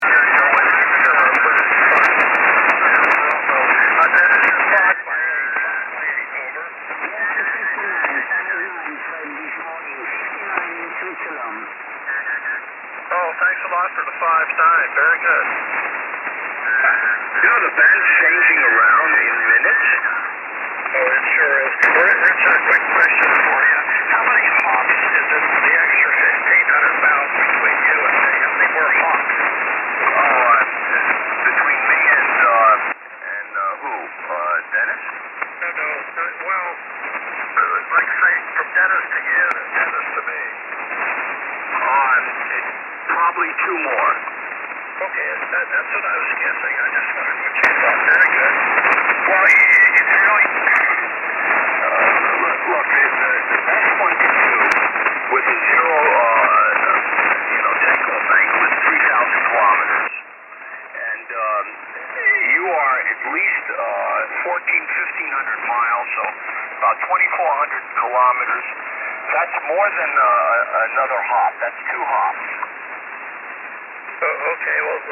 Ohessa aito bandiäänitys Yhdysvalloista ja samalla ensimmäinen vastaanotin-antennitesti, jossa on 4xLIRA on vertailussa muuhun kuunteluantenniin.
Vertailuantennina hänellä on ”4 sq” eli ”Four Square”-antenni, joka perustuu neljään vaiheistettuun vertikaaliin.